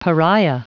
Prononciation du mot pariah en anglais (fichier audio)
Prononciation du mot : pariah